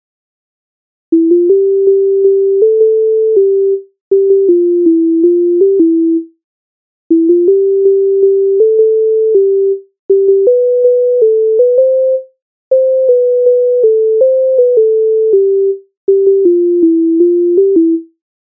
дитяча